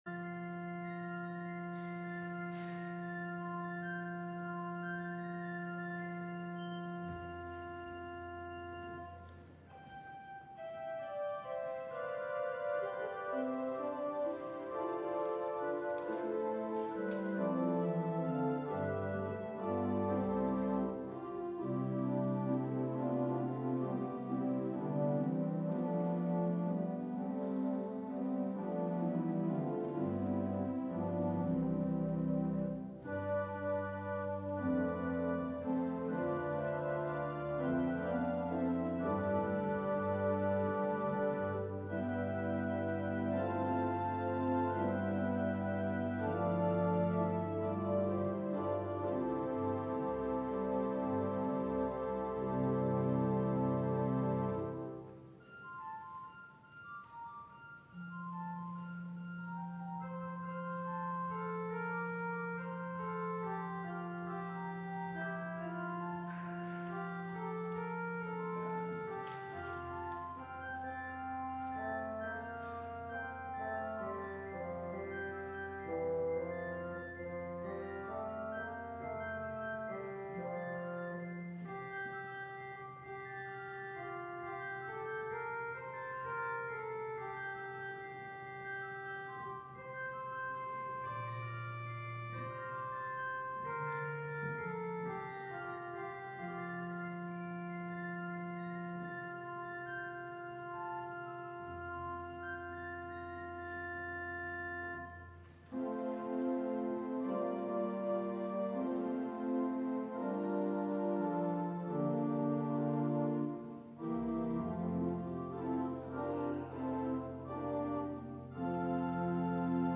LIVE Evening Worship Service - Jesus’ Words About Church Leadership
Congregational singing—of both traditional hymns and newer ones—is typically supported by our pipe organ.